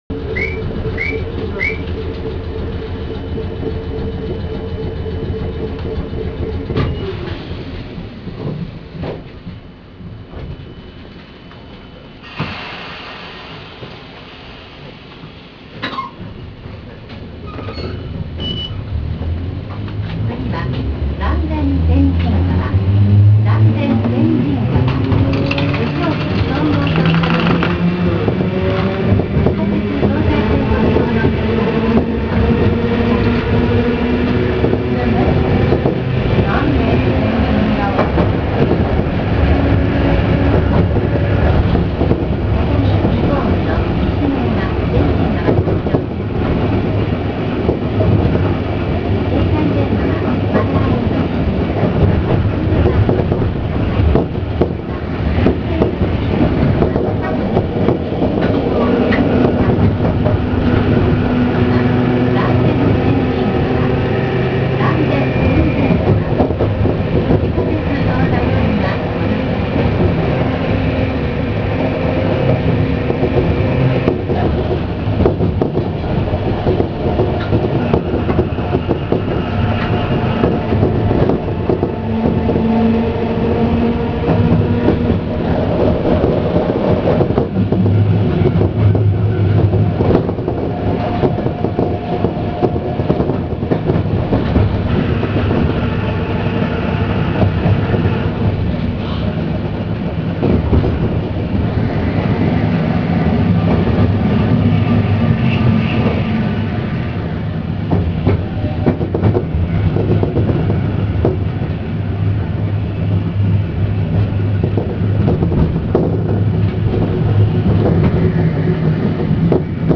・モボ611形走行音
【嵐山本線】山ノ内→嵐電天神川（2分15秒：738KB）
旧型車の機器流用により登場した車両であるため、この車両も吊り掛け式。それも、路面電車によくある軽そうな音ではなくなかなか重々しい音を出すため、何とも聞きごたえがあります。